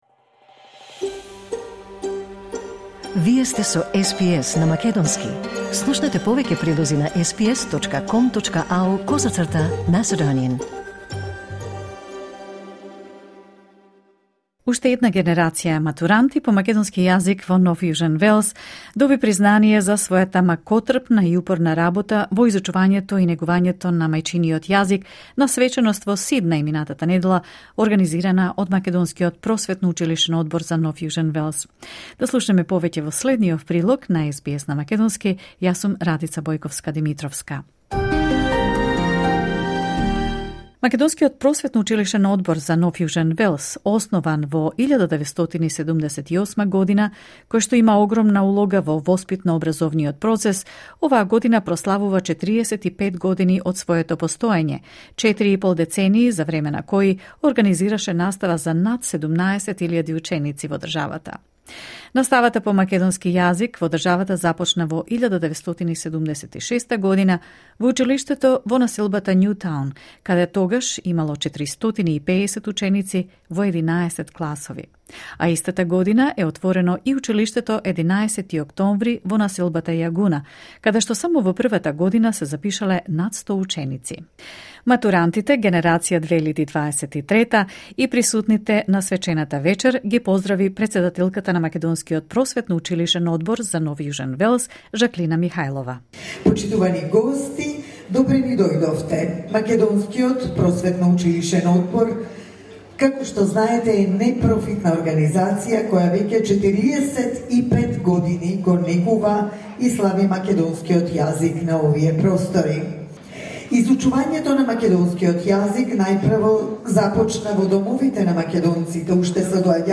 Учениците по македонски јазик ја одржаа матурската вечер во Сиднеј, во организација на Македонскиот Просветно-Училишен Одбор за НЈВ, кој ова година прославува 45 години од своето основање.